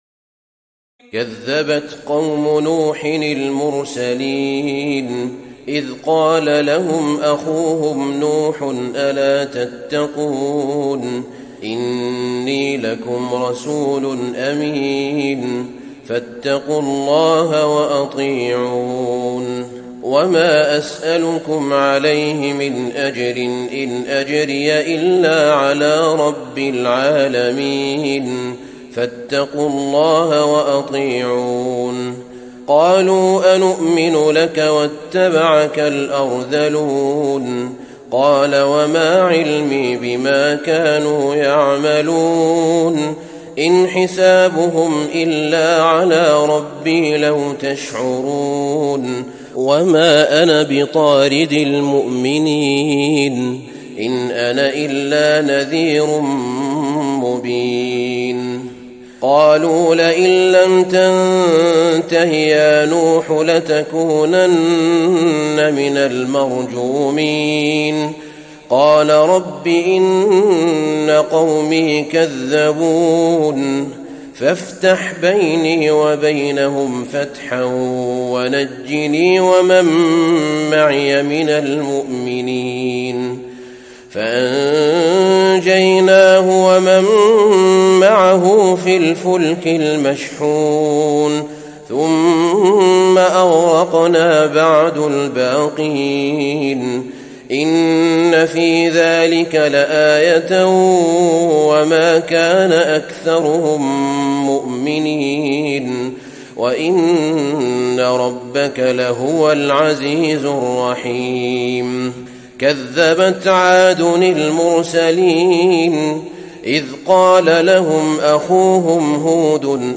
تراويح الليلة الثامنة عشر رمضان 1435هـ من سورتي الشعراء (105-227) والنمل (1-58) Taraweeh 18 st night Ramadan 1435H from Surah Ash-Shu'araa and An-Naml > تراويح الحرم النبوي عام 1435 🕌 > التراويح - تلاوات الحرمين